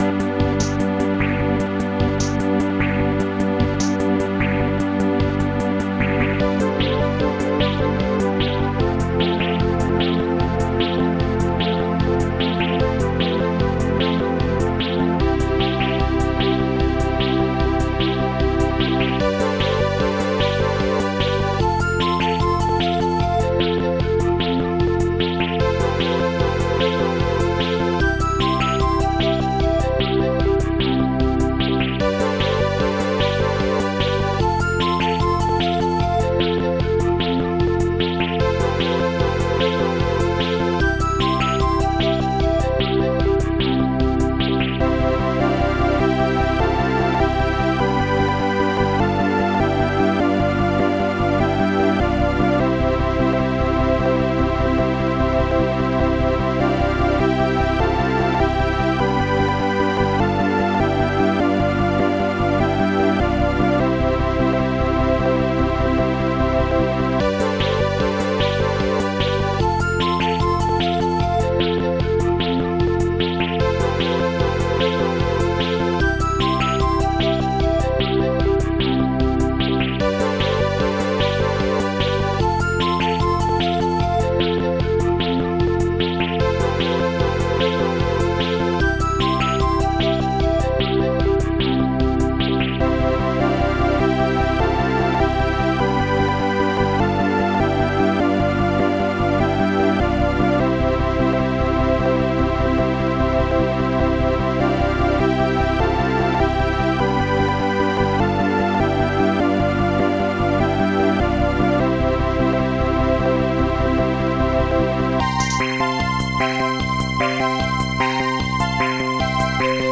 Protracker and family
st-02:stringsmaj
st-01:bassdrum2
st-01:popsnare2
st-01:hihat2
st-02:loguitar